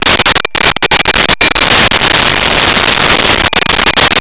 Bells002
bells002.wav